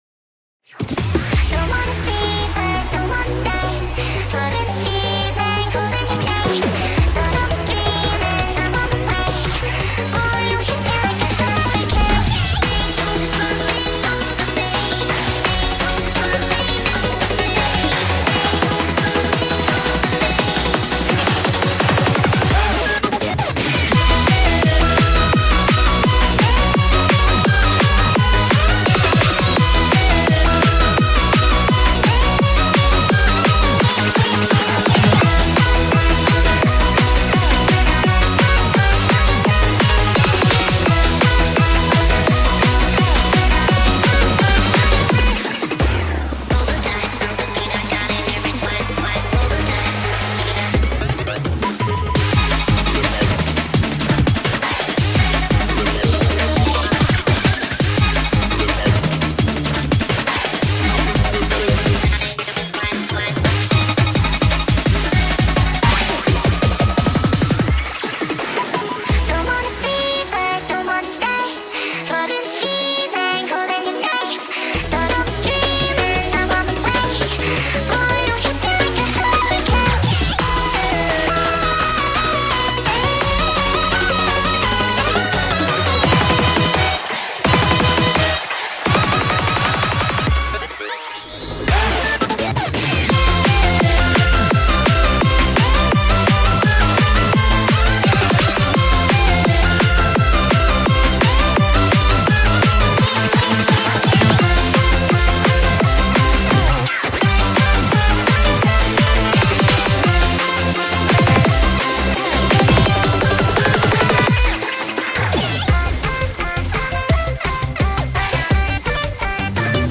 BPM170
Audio QualityPerfect (Low Quality)